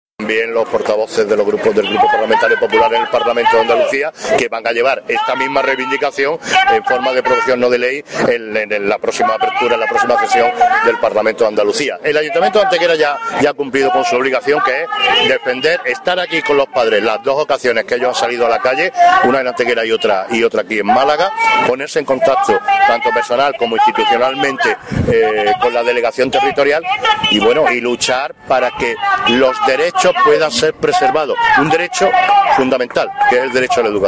Generar Pdf martes 30 de agosto de 2016 El Alcalde de Antequera asiste en Málaga a la concentración de apoyo al Colegio Público Rural Atalaya Generar Pdf FOTOS Y AUDIOS Fotografías alusivas a la presencia hoy del alcalde de Antequera, Manolo Barón, en la concentración desarrollada en Málaga por parte de padres y alumnos del Colegio Rural Atalaya a las puertas de la Delegación Provincial de la Consejería de Educación de la Junta de Andalucía, protestando sobre la pérdida de profesores y líneas educativas en dicho centro. También se incluyen cortes de audio con declaraciones del Alcalde. Cortes de voz M. Barón (1) 614.75 kb Formato: mp3 M. Barón (2) 235.03 kb Formato: mp3